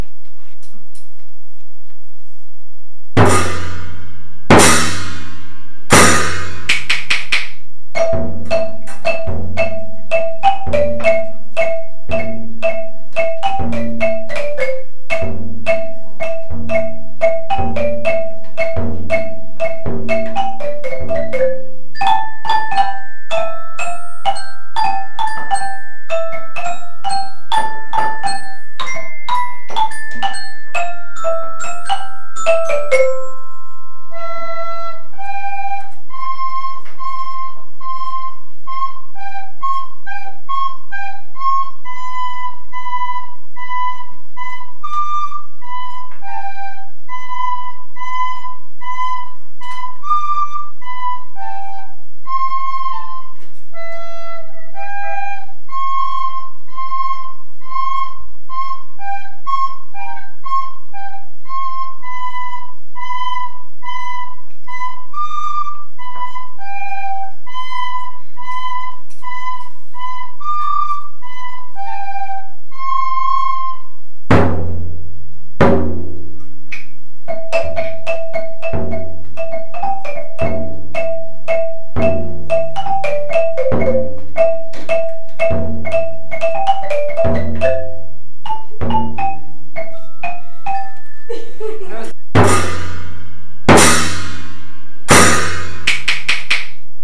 Per aquest motiu hem fet una versió amb els instruments que tenim a l’escola d’un dels fragments de la famosa obra.